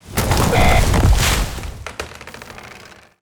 GetHit.wav